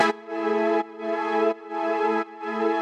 Index of /musicradar/sidechained-samples/170bpm